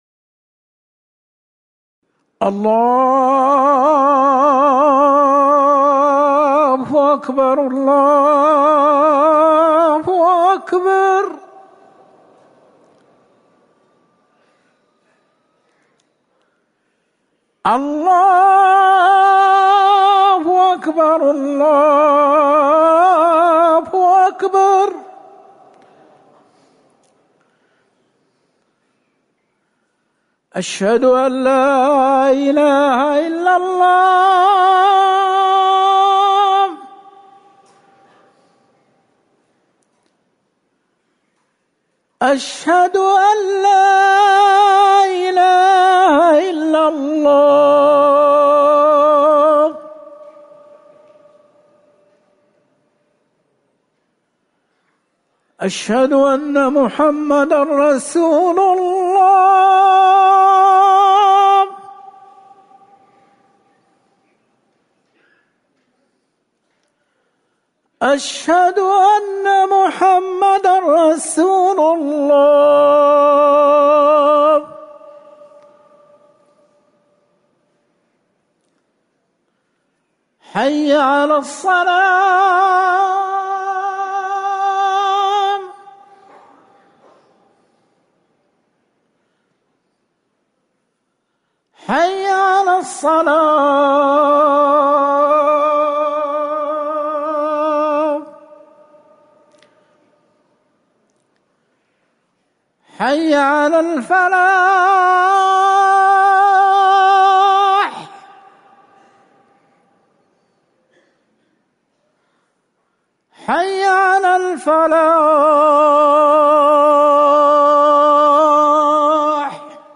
أذان المغرب
تاريخ النشر ٨ صفر ١٤٤١ هـ المكان: المسجد النبوي الشيخ